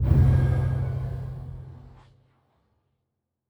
Fantasy Interface Sounds
Special Click 08.wav